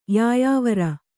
♪ yāyāvara